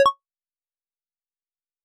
Paste.wav « Normal_Mode « Resources - auditory.vim - vim interface sounds